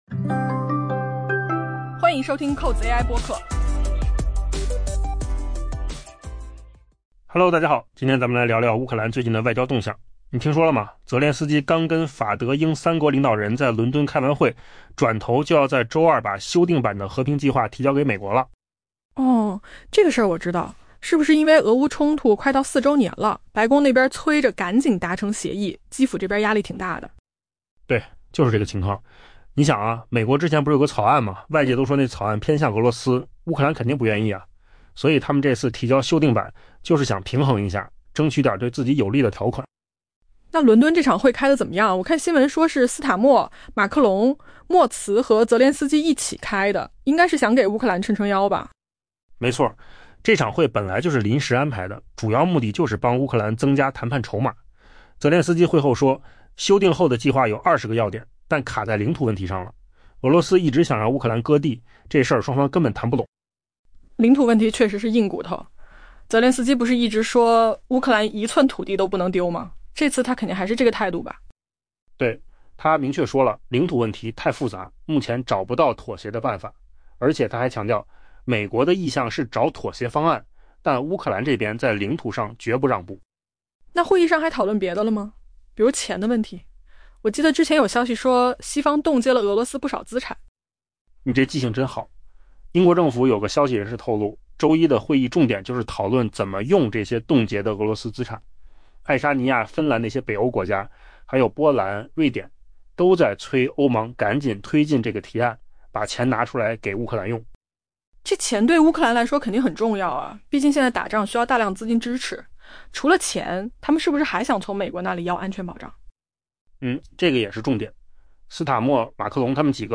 AI 播客：换个方式听新闻 下载 mp3 音频由扣子空间生成 在乌克兰总统泽连斯基与法、德、英三国领导人于伦敦举行会谈后， 乌克兰将于周二向美国分享一份旨在结束俄乌冲突的修订后的和平计划。